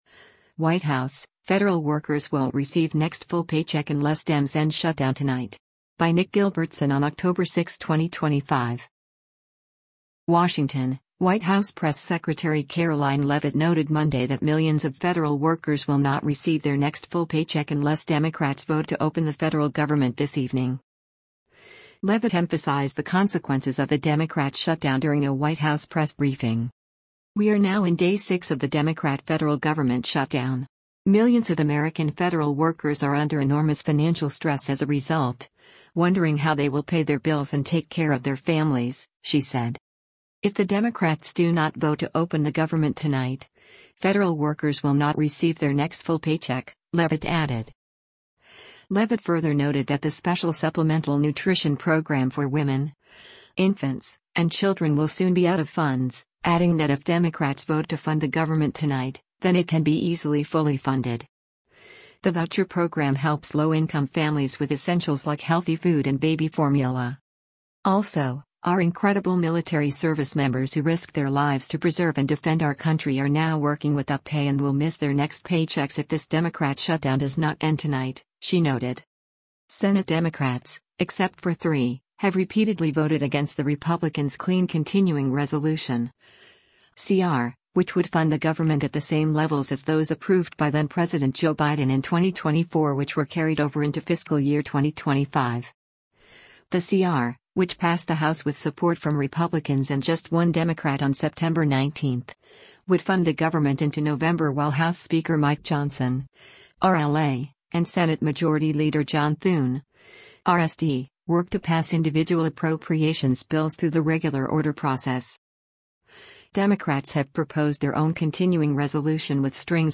WASHINGTON, DC - OCTOBER 06: White House Press Secretary Karoline Leavitt (R) speaks durin
Leavitt emphasized the consequences of the Democrats’ shutdown during a White House press briefing.